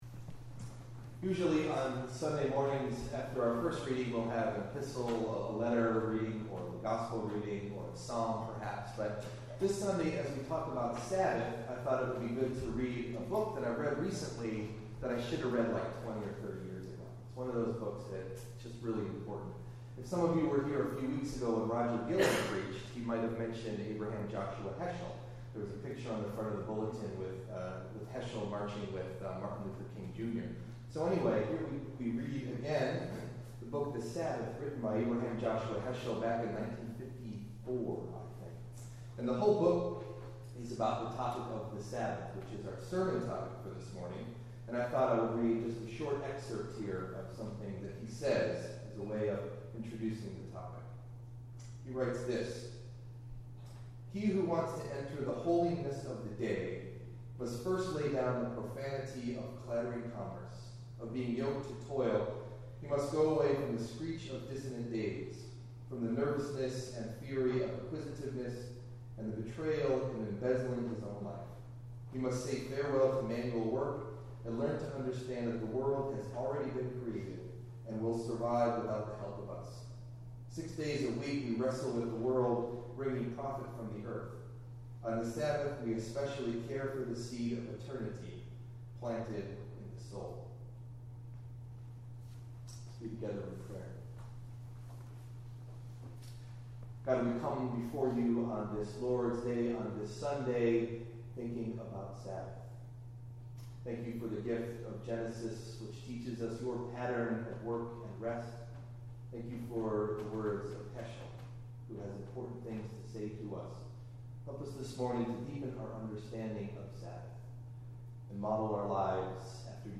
Delivered at: The United Church of Underhill